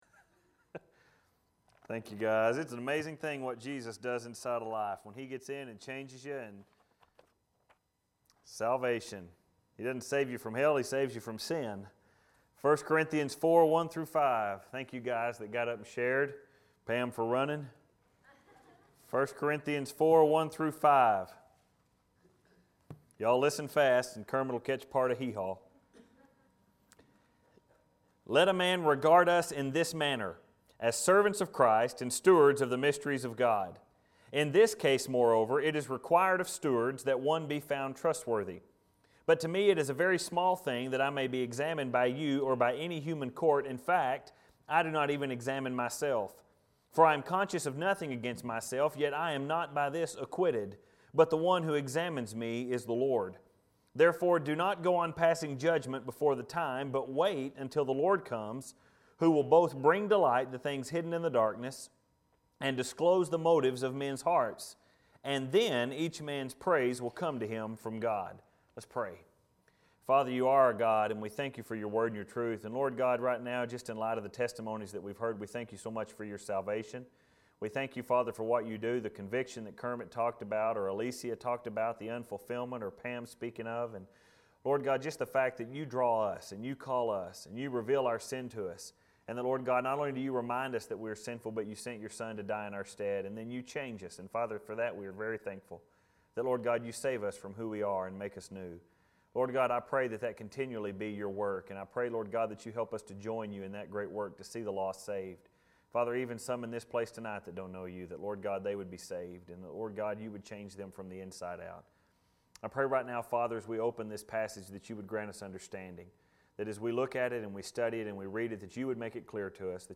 Filed Under: Sermons Tagged With: Corinthians